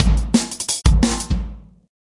半循环的鼓声样本 " Hihat9开放
标签： 声学
声道立体声